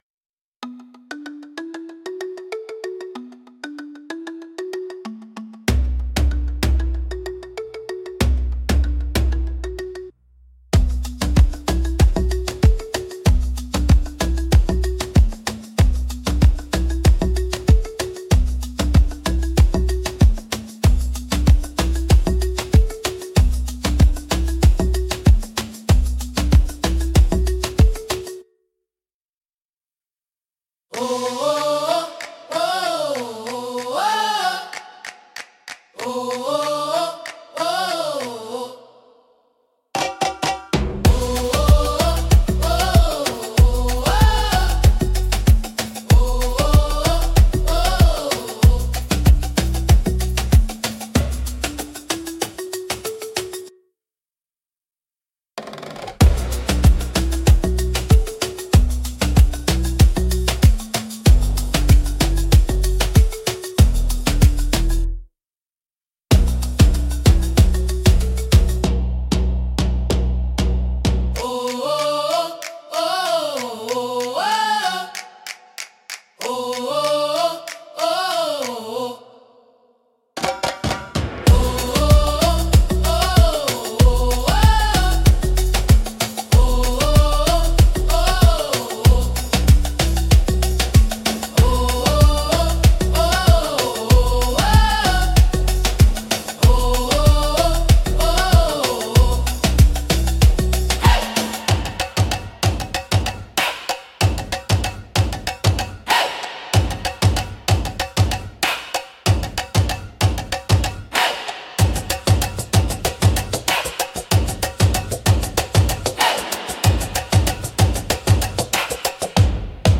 迫力と神秘性が共存するジャンルです。